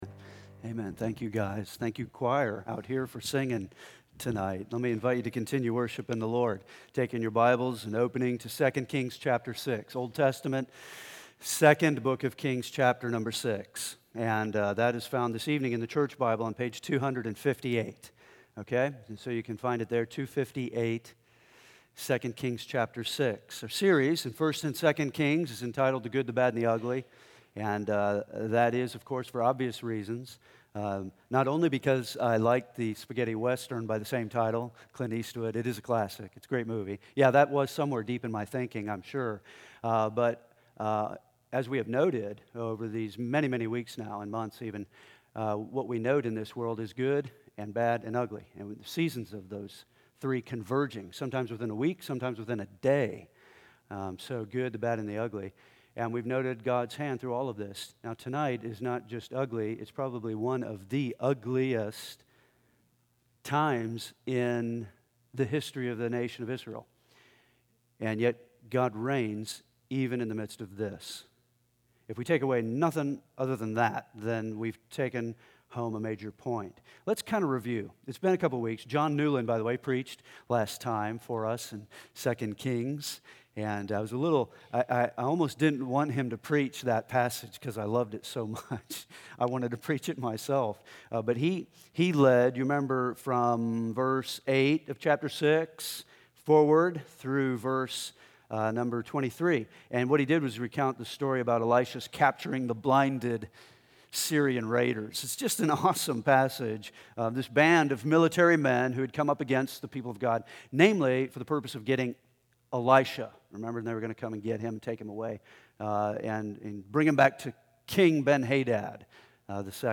2016 The Good The Bad The Ugly 2 Kings This is an evening sermon with no manuscript attached.